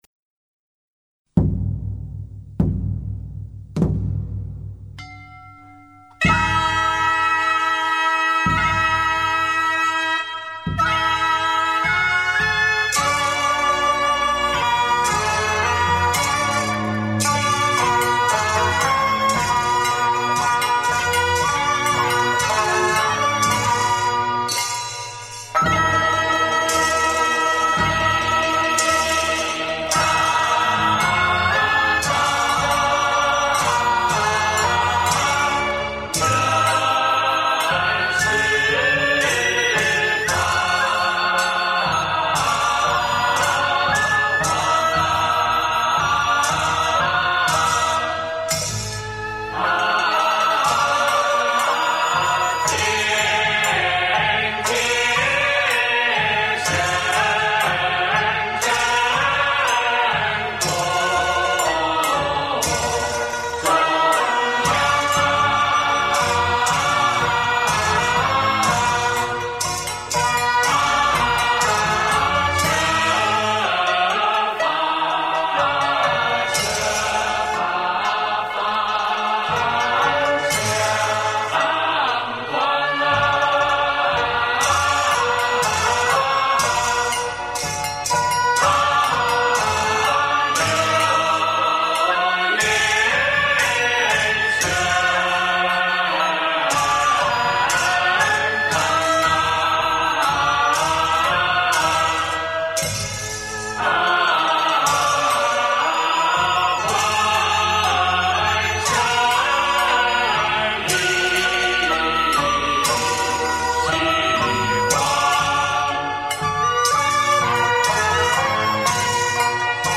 选取道教仪式中的部分曲目，请上海名家演唱，充分表现出道家韵腔的况味。